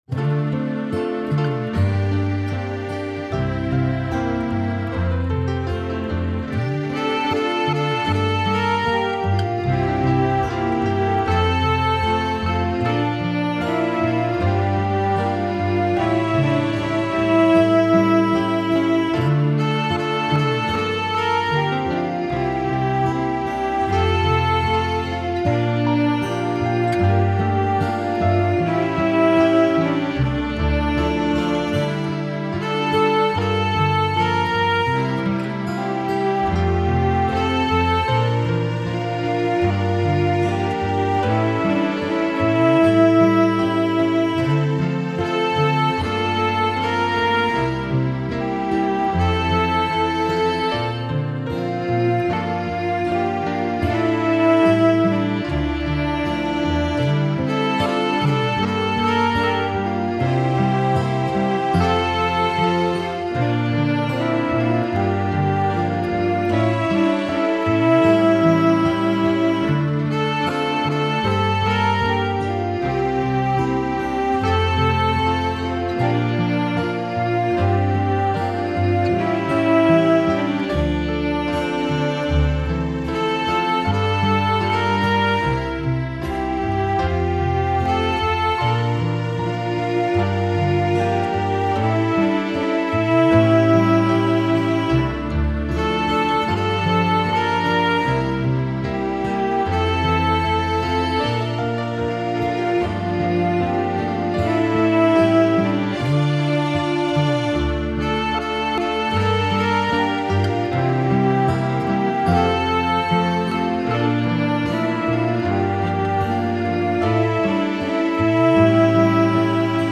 This version has nice bongos.